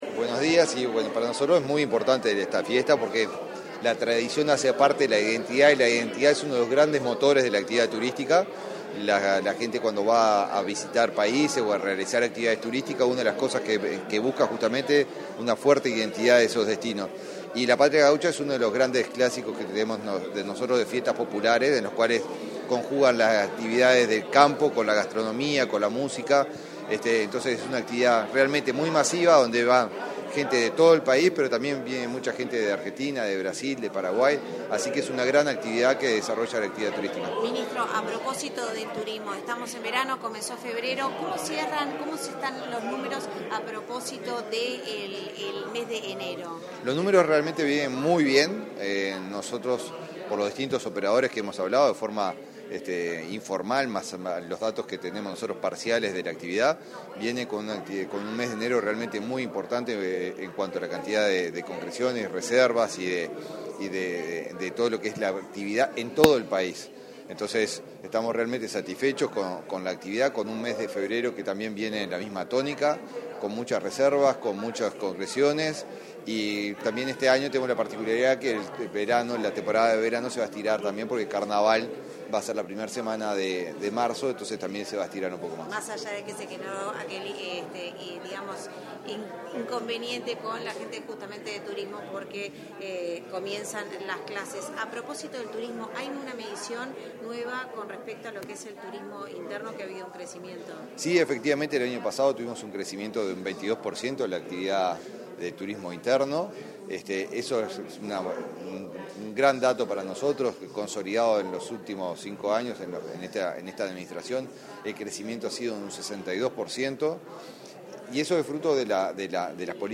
Declaraciones a la prensa del ministro de Turismo, Eduardo Sanguinetti
sanguinetti prensa.mp3